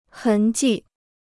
痕迹 (hén jì): vestige; mark.